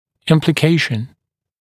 [ˌɪmplɪ’keɪʃn][ˌимпли’кейшн]подразумеваемое следствие, последствия, результаты, скрытый смысл